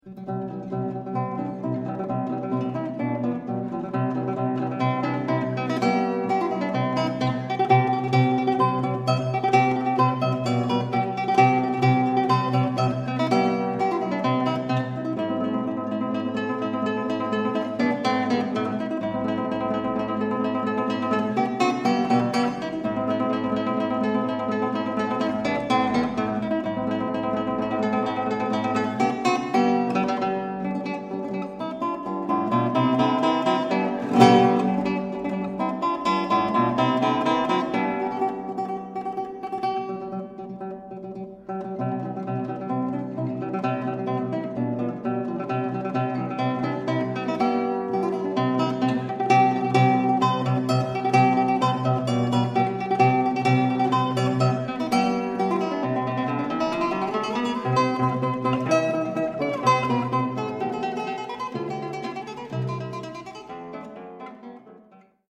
Skladby pro osmistrunnou kytaru
"Dřevěný kostelík", Blansko 2010